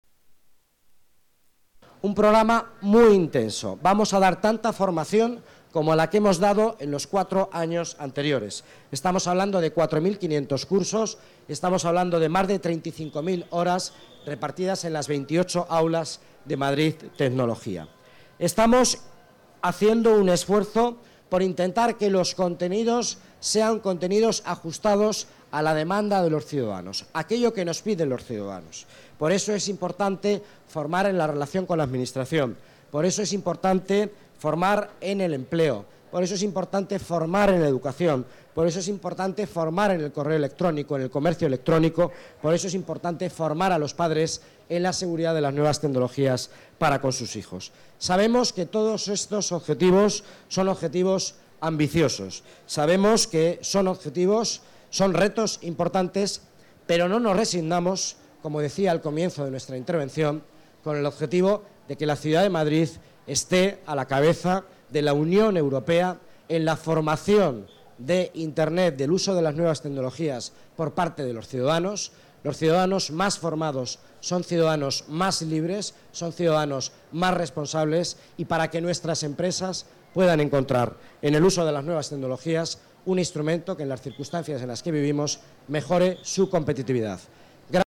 Nueva ventana:Declaraciones del delegado de Economía, Miguel Ángel Villanueva: Aulas Madrid Tecnología